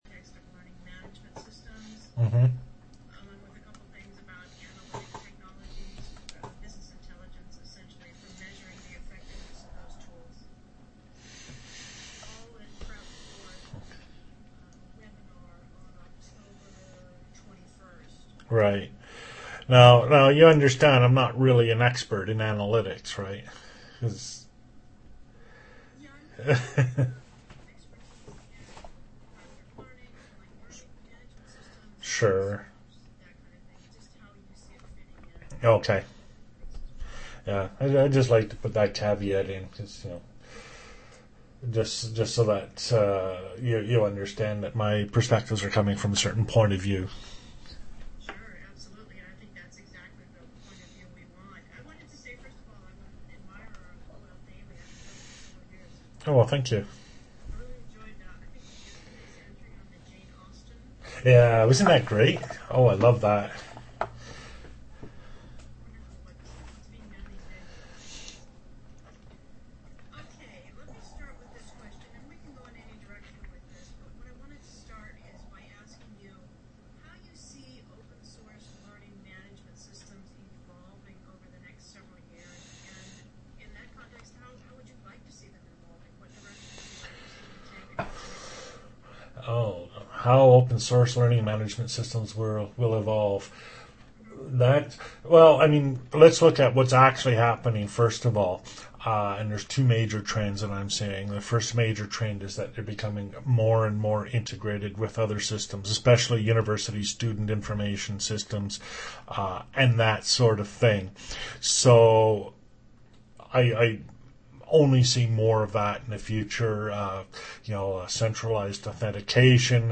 Looking at trends such as federated authentication and integrated modules. As time goes by the LMSs are becoming less stand-alone and more integrated with a wider ecosystem of services and applications. Interviewer's voice is very very faint.